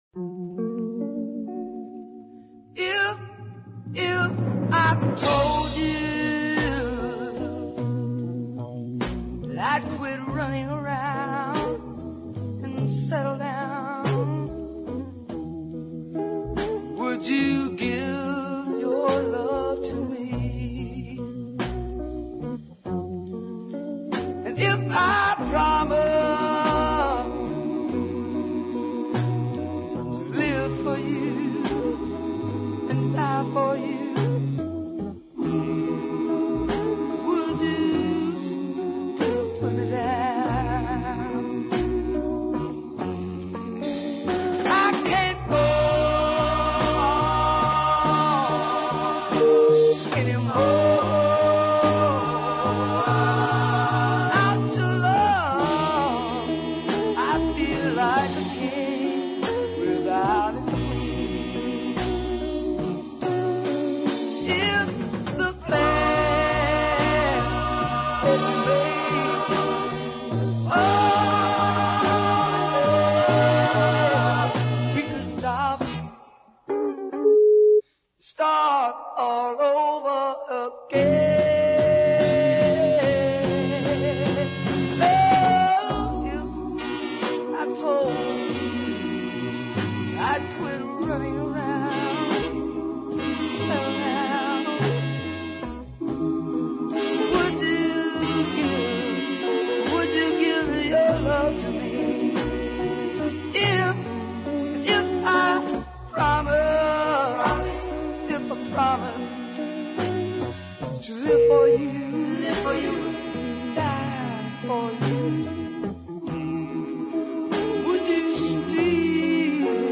Arguably the best deep soul track on this Cincinnatti label.